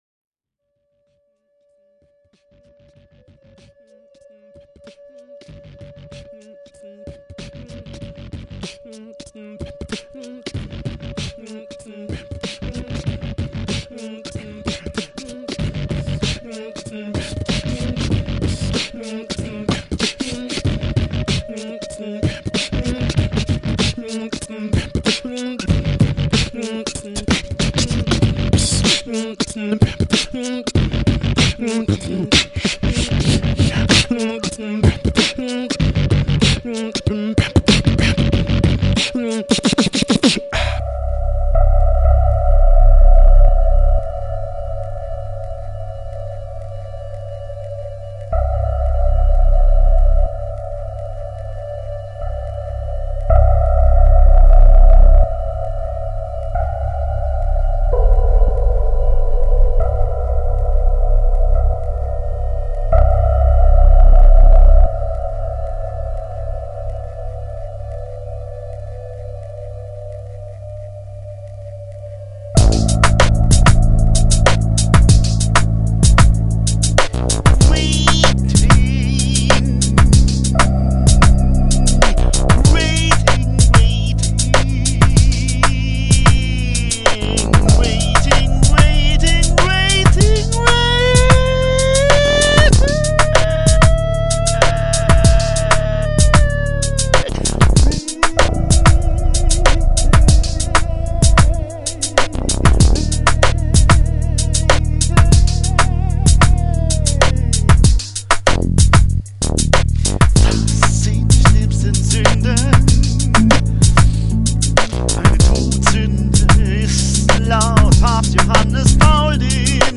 tenor/countertenor & electronics